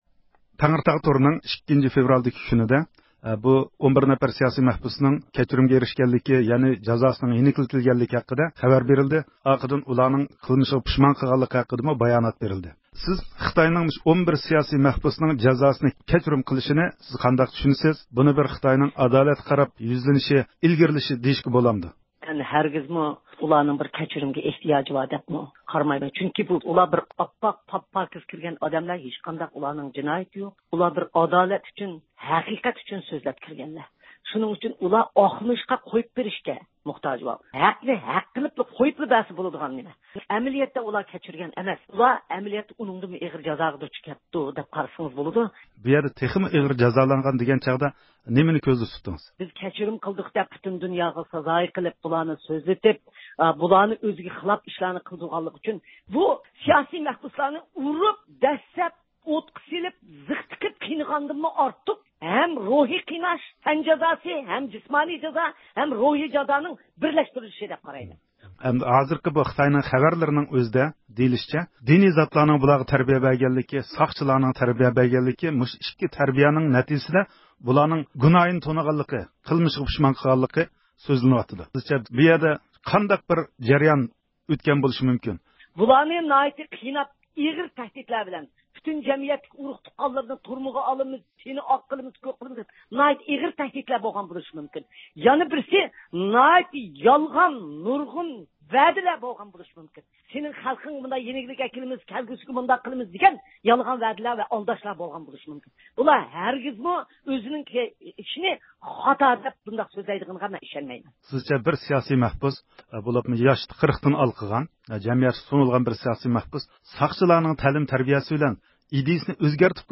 خىتاينىڭ 11 نەپەر ئۇيغۇر سىياسىي مەھبۇسنىڭ جازاسىنى يېنىكلىتىش ھۆكۈمى ھەققىدە بۈگۈن رادىئومىزدا پىكىر بايان قىلغان ئۇيغۇر مىللىي ھەرىكىتى رەھبىرى رابىيە قادىر خانىم، ھۆكۈمدە ماھىيەتلىك بىر ئۆزگىرىش يوقلۇقىنى، مەھبۇسلارنى پۇشايمان باياناتى بەرگۈزۈش ئارقىلىق، ئۇلارنىڭ غۇرۇرى ۋە ئىززەت-ھۆرمىتىگە ھۇجۇم قىلىنغانلىقىنى ئىلگىرى سۈردى.